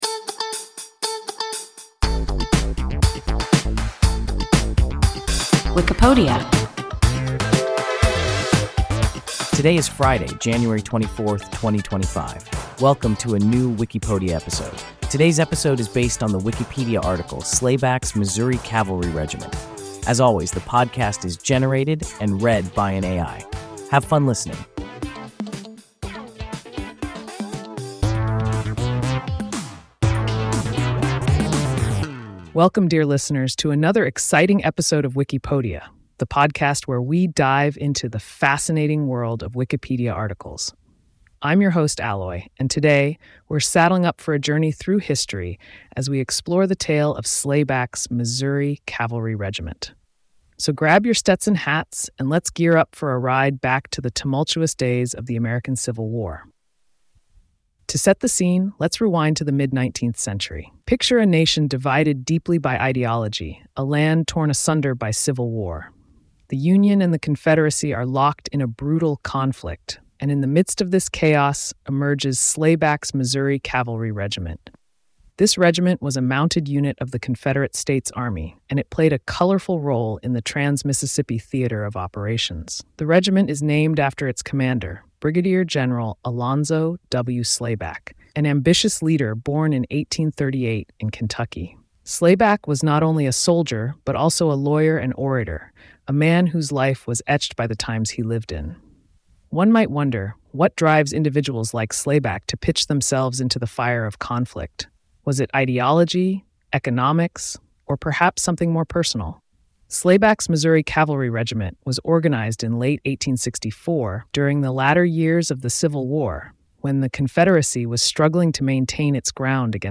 Slayback’s Missouri Cavalry Regiment – WIKIPODIA – ein KI Podcast